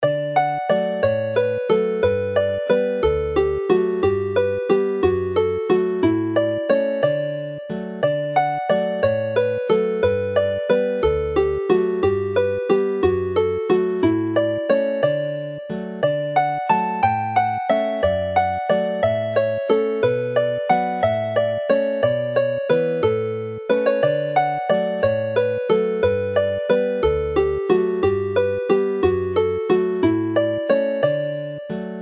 This set of jigs
Play the melody slowly